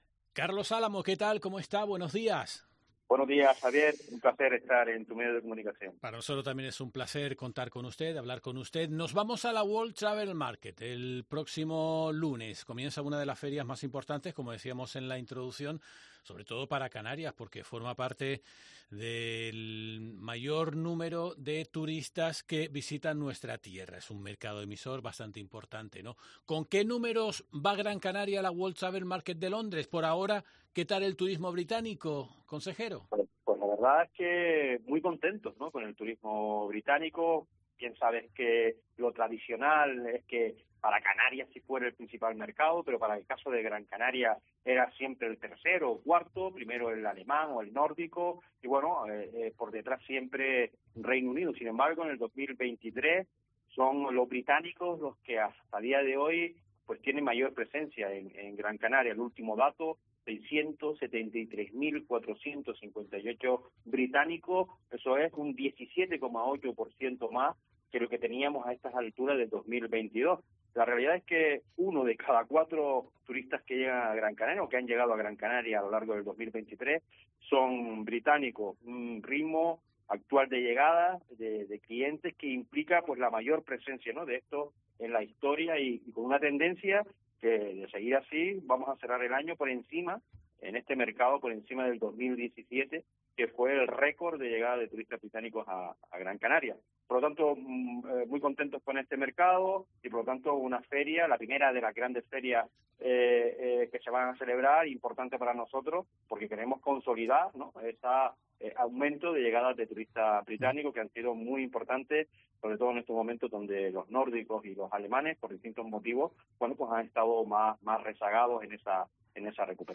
Carlos Álamo, consejero de Turismo del Cabildo de Gran Canaria